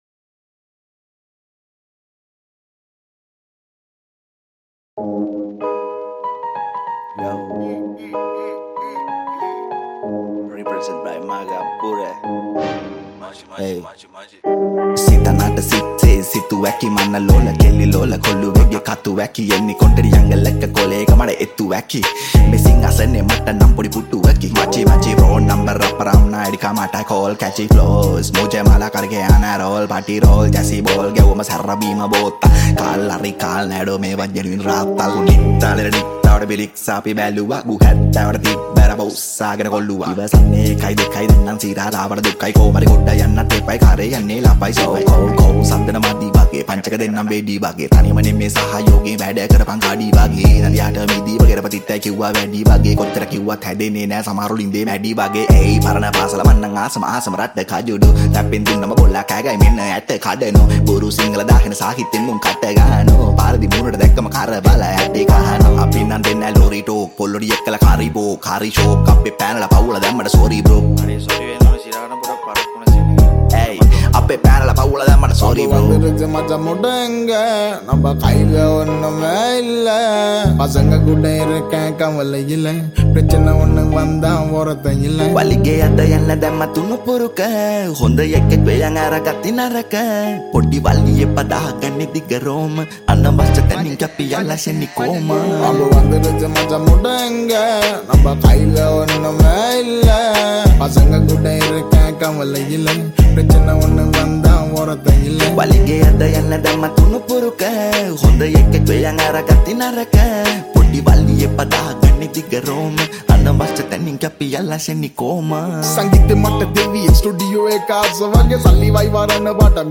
High quality Sri Lankan remix MP3 (3.5).
remix